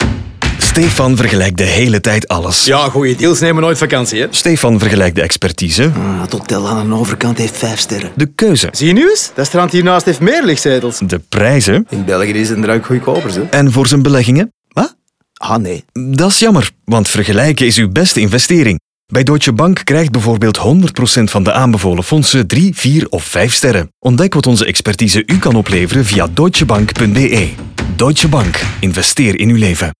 En laten we de radiospot niet vergeten, Hierin komt Stéphane aan het woord, een man die de hele tijd alles vergelijkt.
DeutscheBank-DeVergelijker-Radio-NL-30s-Vakantie-051217.mp3